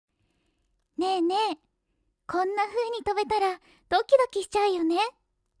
丁寧・高品質・リーズナブルなプロの女性ナレーターによるナレーション収録